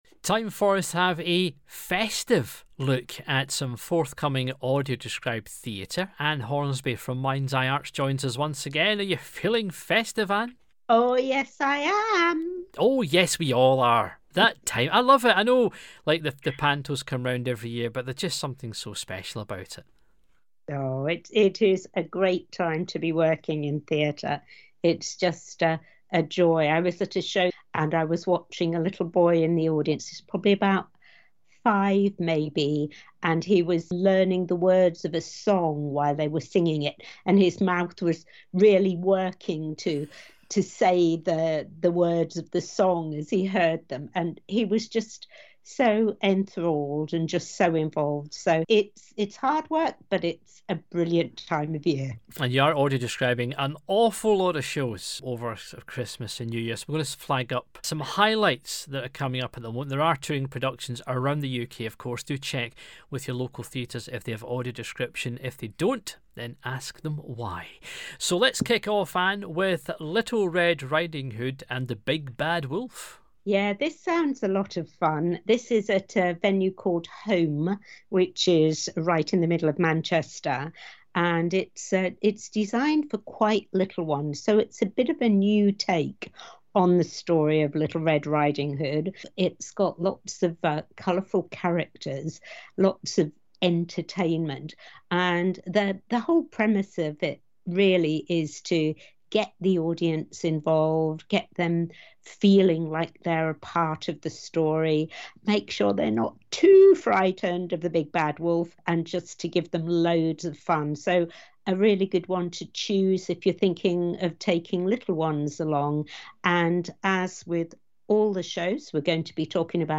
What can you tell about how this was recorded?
Audio Described Panto Highlights From MindsEye Description